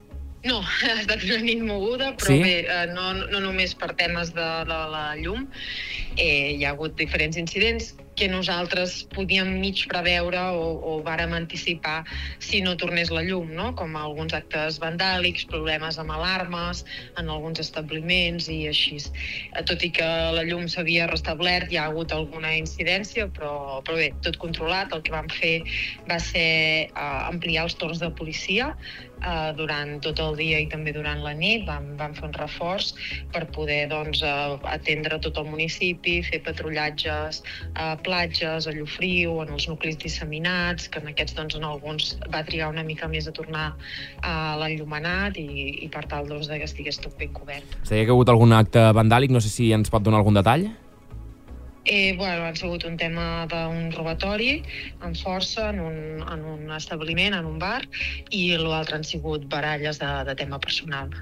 Al Supermatí hem fet un programa especial per poder recollir els testimonis d'alcaldes, ciutadans i empresaris de la comarca per veure com van afrontar les hores sense llum d'aquest dilluns